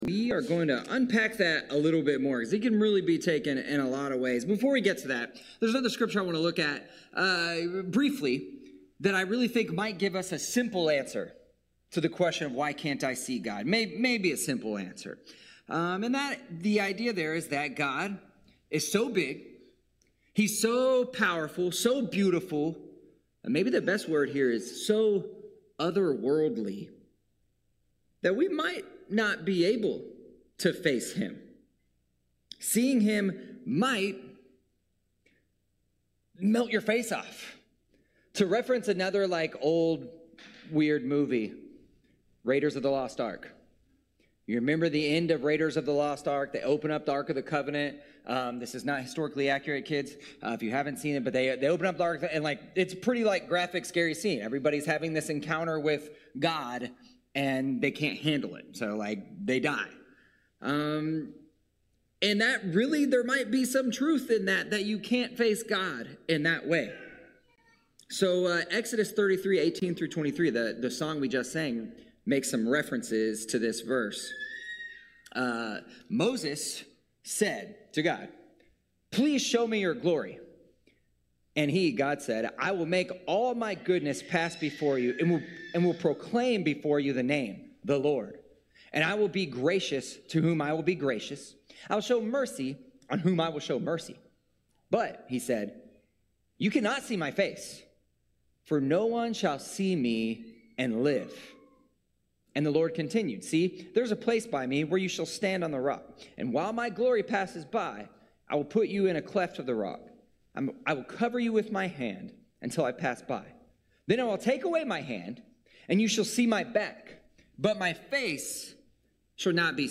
This week's bulletin - 6/9/2024 More from the series: Kid Questions ← Back to all sermons